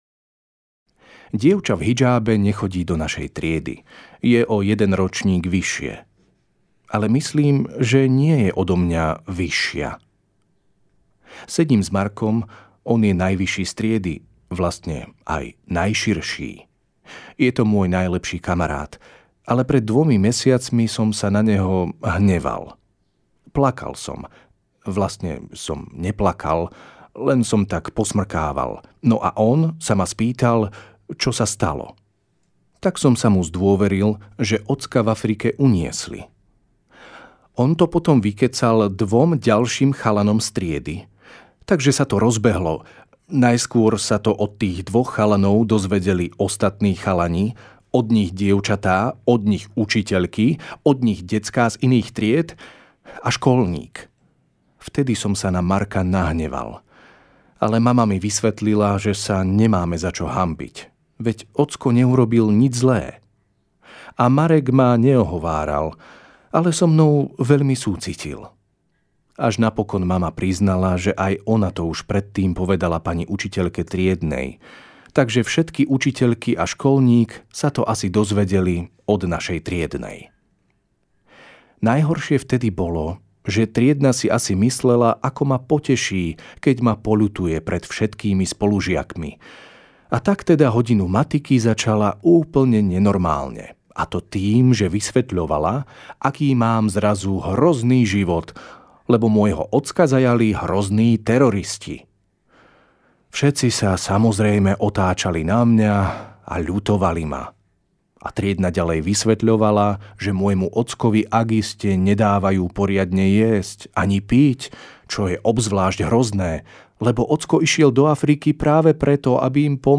Norm�lny Peter, ve�mi tehotn� mama a dobr� samarit�n (audiokniha)